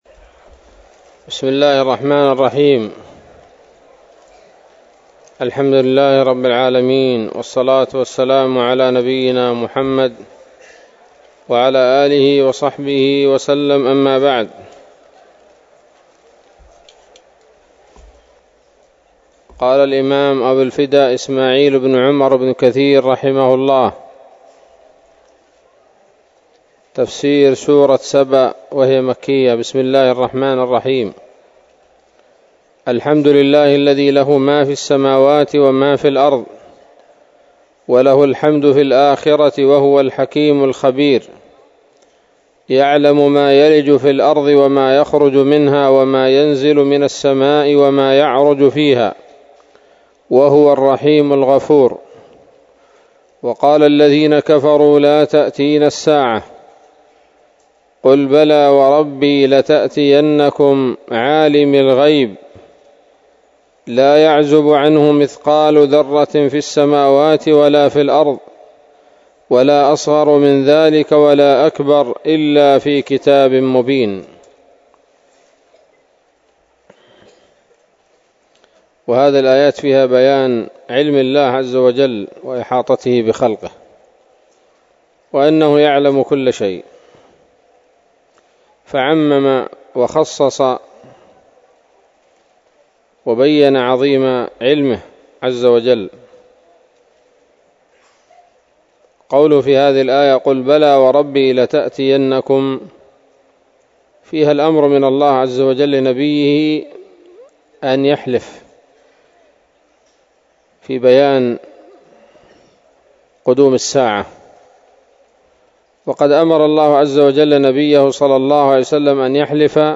الدرس الأول من سورة سبأ من تفسير ابن كثير رحمه الله تعالى